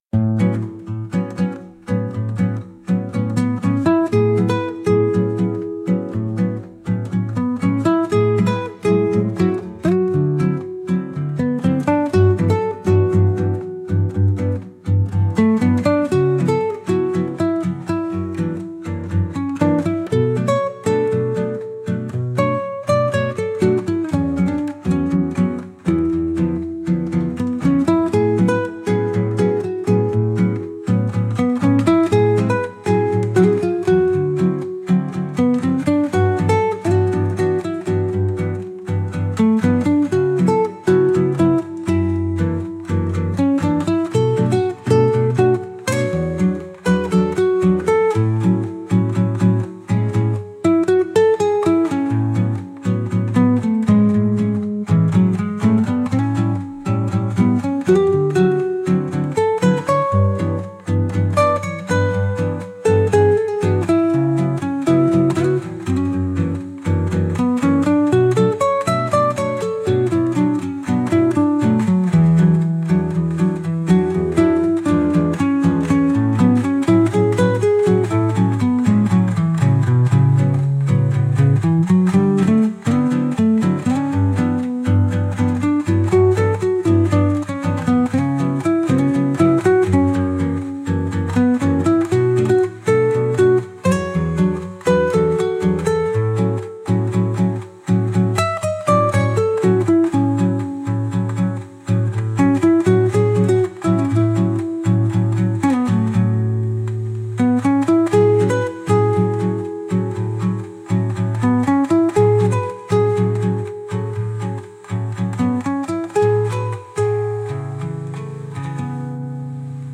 朝のカフェで聞くようなボサノバ音楽です。